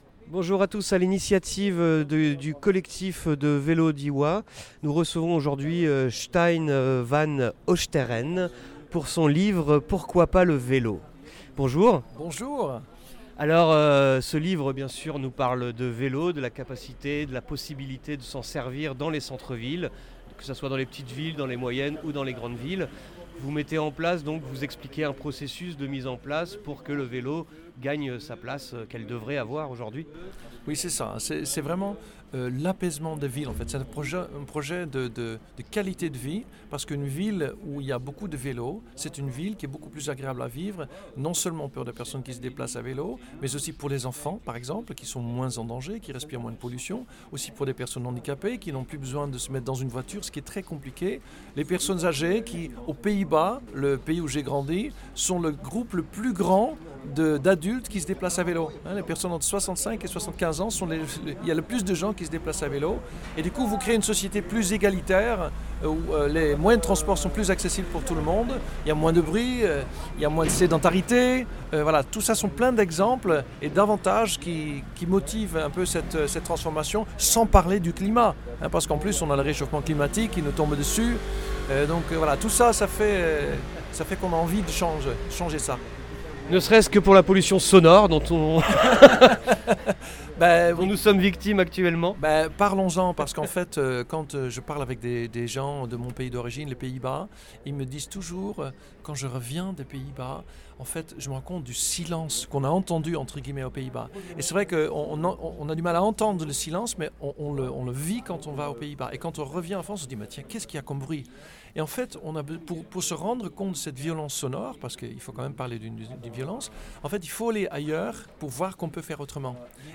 Interview pour sa bande-dessinée Pourquoi pas le vélo ?